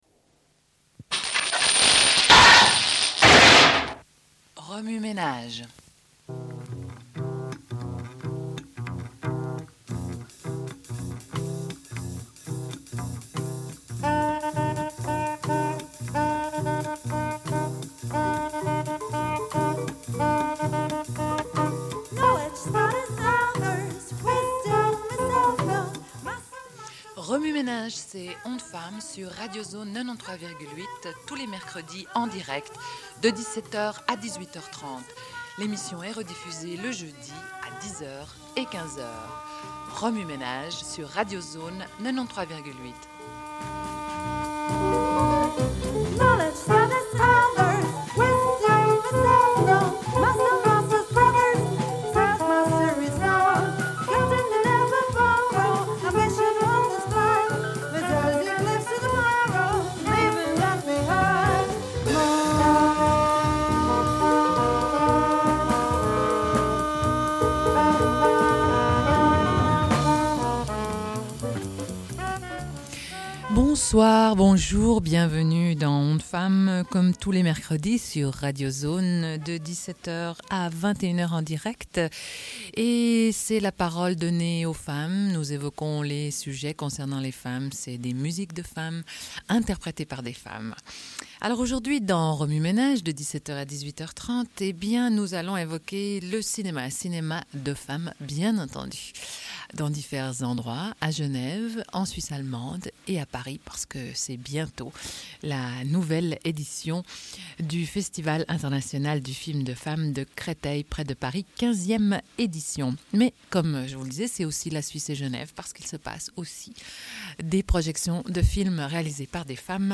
Une cassette audio, face A31:23